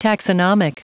Prononciation du mot taxonomic en anglais (fichier audio)